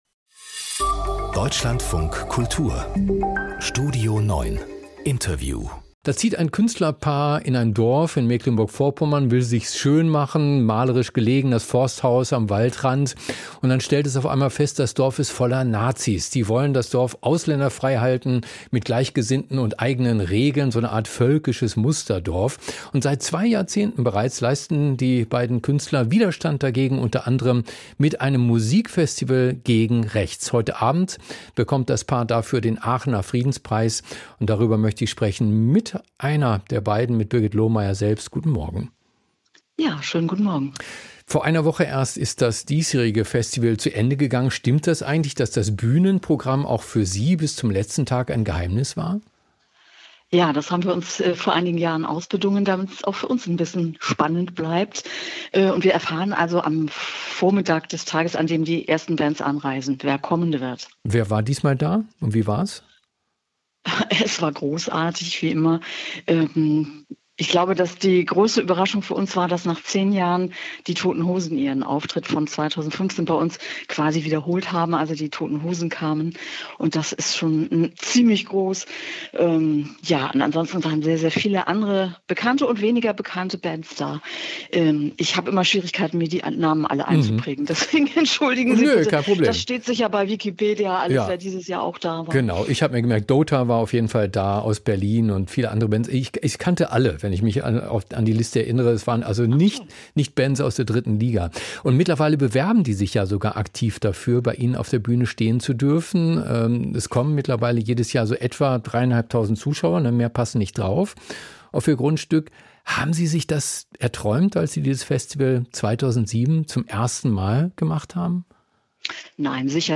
Das Interview im Deutschlandfunk Kultur greift kulturelle und politische Trends ebenso auf wie... Mehr anzeigen